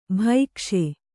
♪ bhaikṣe